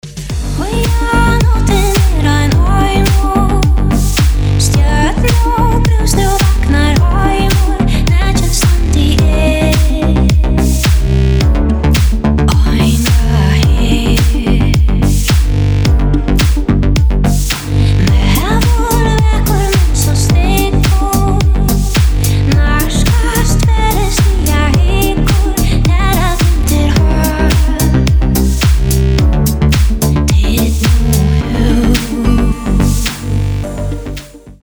• Качество: 320, Stereo
deep house
nu disco
красивый женский голос
Indie Dance